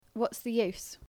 /j/ yacht versus / dʒ / jot